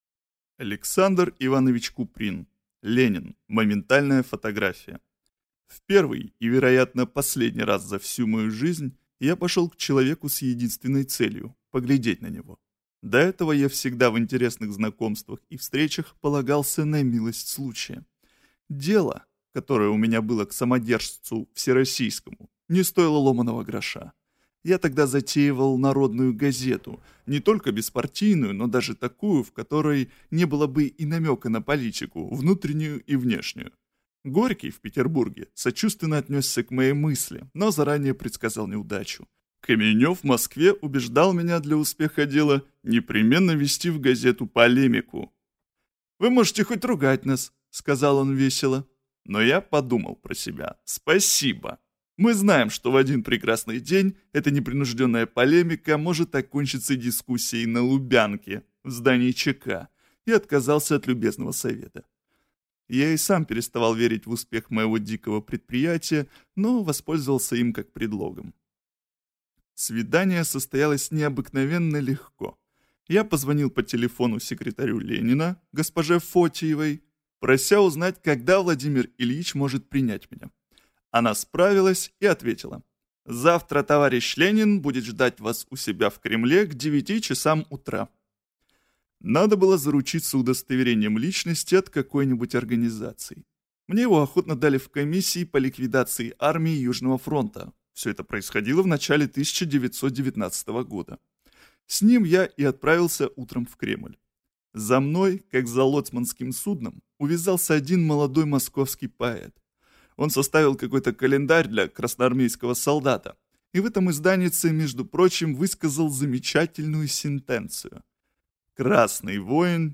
Аудиокнига Ленин (Моментальная фотография) | Библиотека аудиокниг